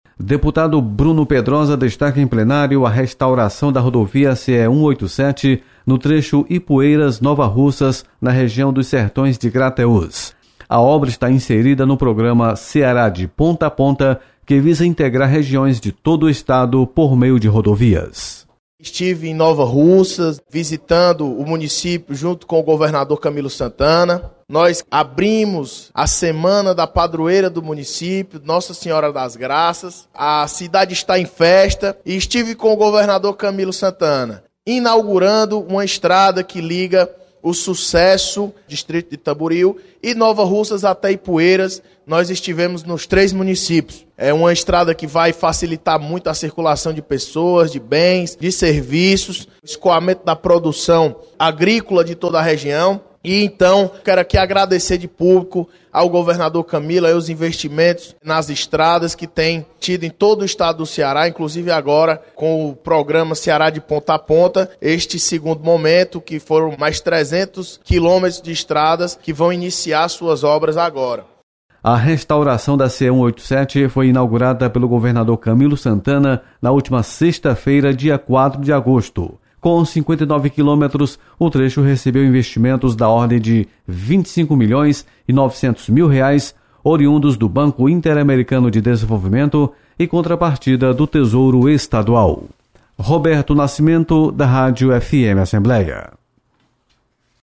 Deputado Bruno Pedrosa destaca restauração da rodovia estadual em Nova Russas. Repórter